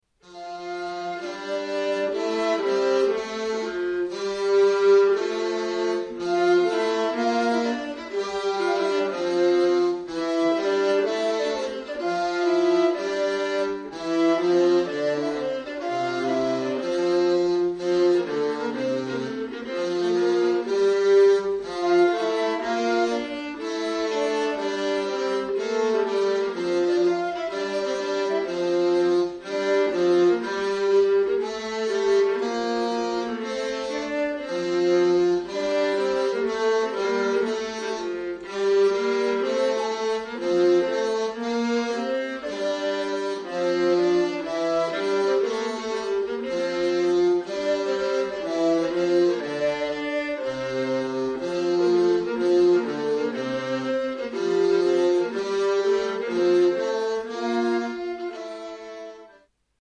Et gaudebit — (tenor + duplum strumentale)